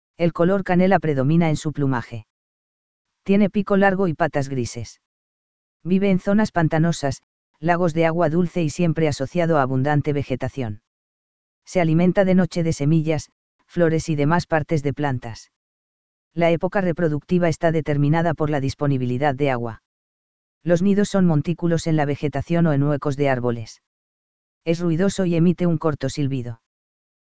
Es ruidoso y emite un corto silbido.